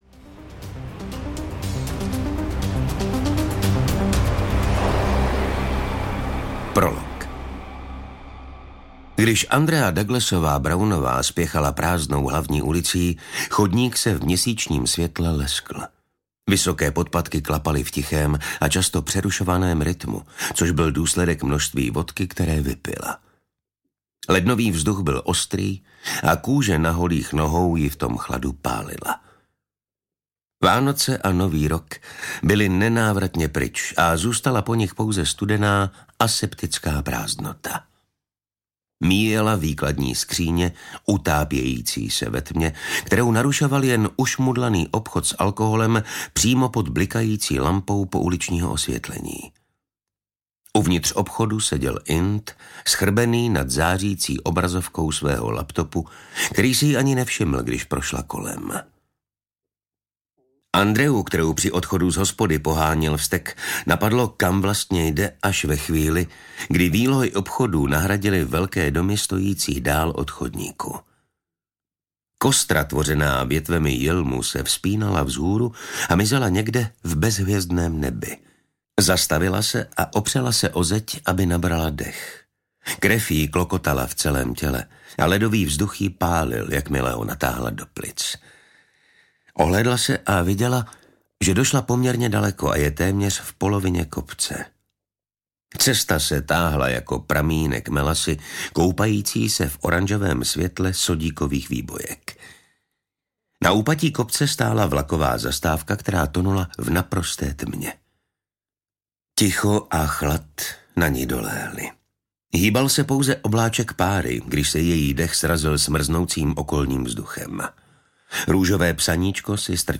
Dívka v ledu audiokniha
Ukázka z knihy